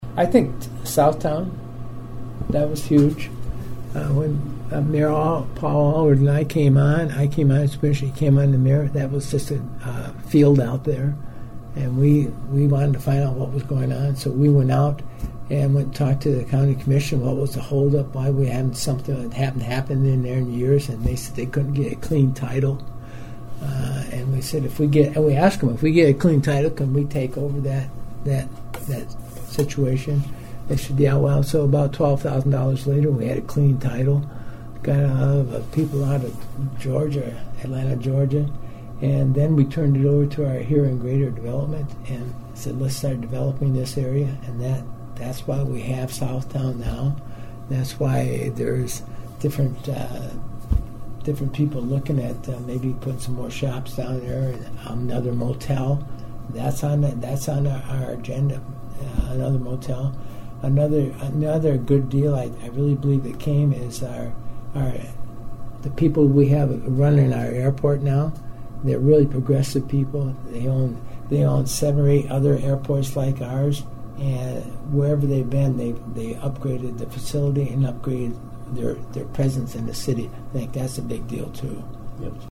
I spoke with Mayor Harrington about the future of the city of Huron including its obstacles to improvement.
Eight years on the commission and 4 as Huron Mayor, Gary talks about his best memories.